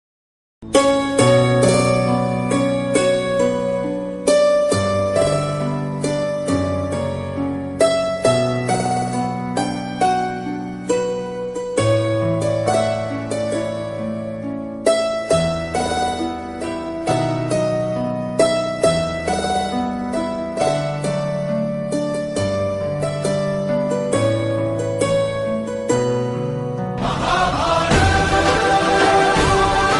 flute song bgm